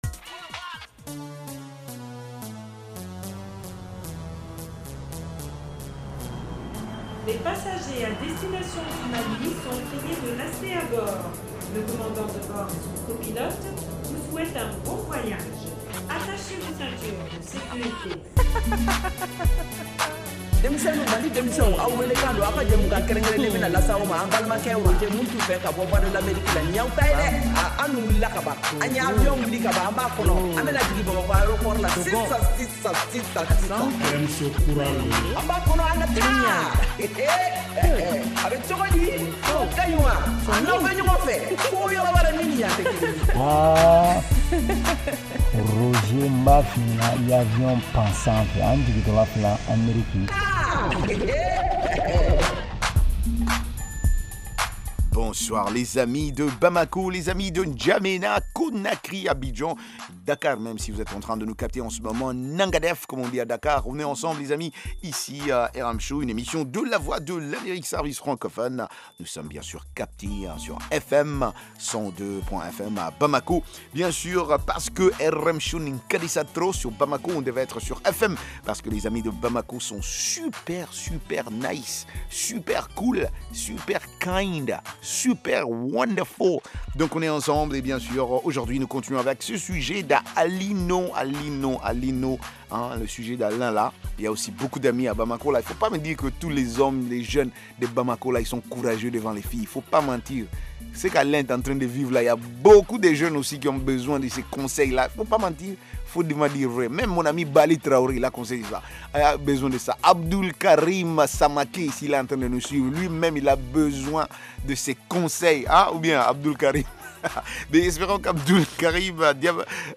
Emission quotidienne de musique et d’entretien avec les auditeurs.